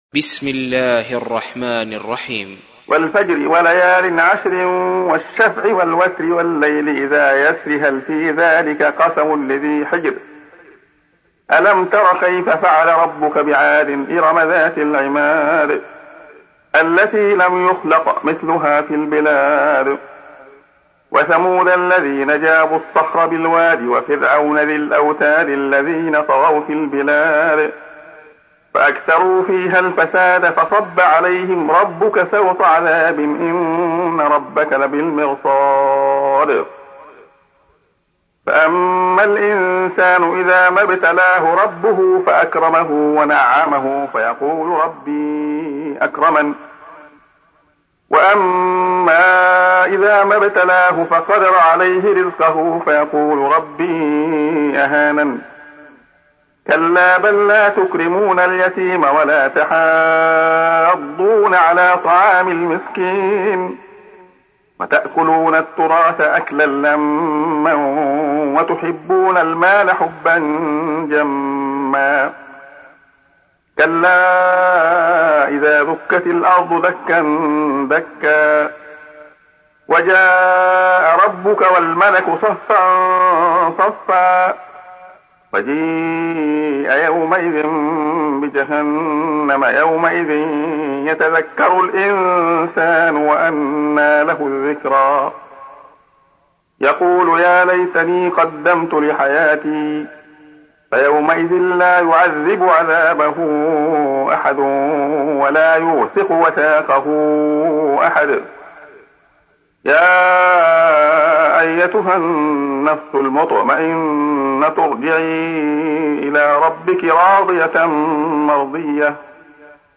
تحميل : 89. سورة الفجر / القارئ عبد الله خياط / القرآن الكريم / موقع يا حسين